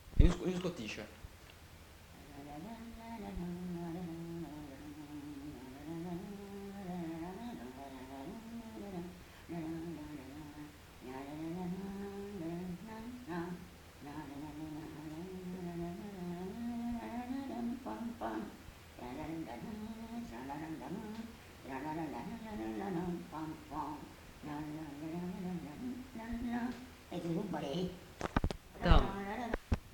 Scottish (fredonné)
Lieu : Mas-Cabardès
Genre : chant
Effectif : 1
Type de voix : voix de femme
Production du son : fredonné
Danse : scottish